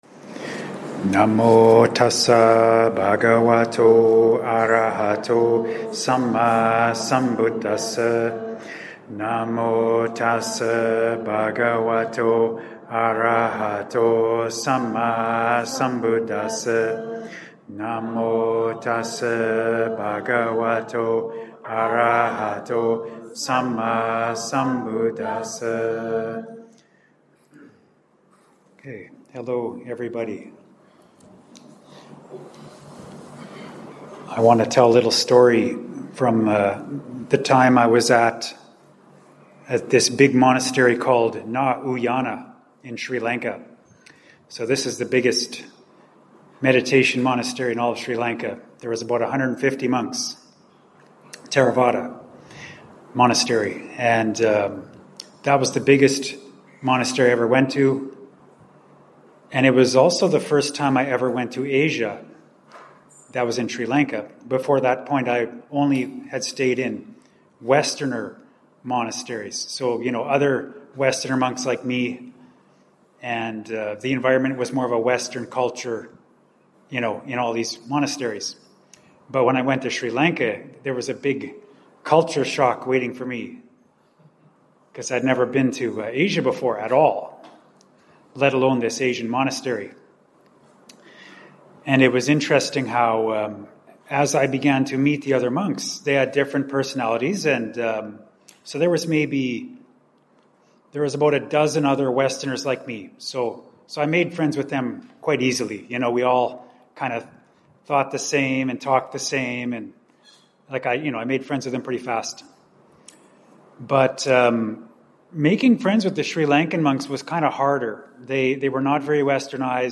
Dhamma Talk Audio 64: Seeing the Positive
Recorded at Brahmavihara Buddhist Monastery, Malaysia, given to an audience of about 50, here’s a 17-min recorded audio-only Dhamma Talk - downloadable 12MB .mp3 (Tip: tap and hold to “Download link”, or right click to “Save Link As…“). This was on the occasion of 7 newly ordained monks; July 6th, 2025.